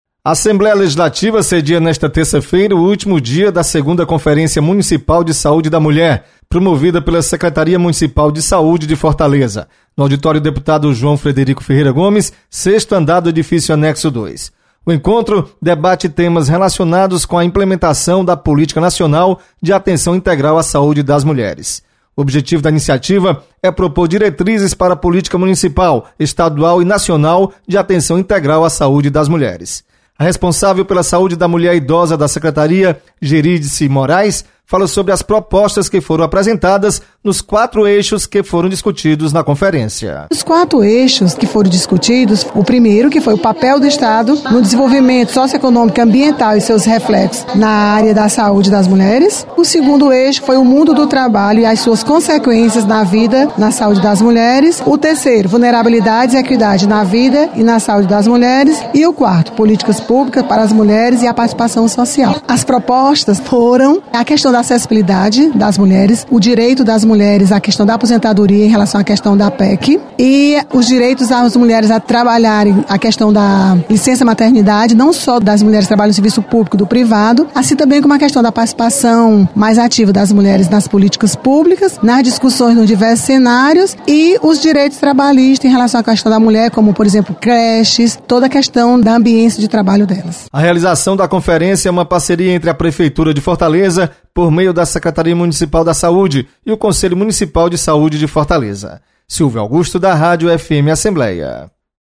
Assembleia sedia Segunda Conferência Municipal da Saúde da Mulher. Repórter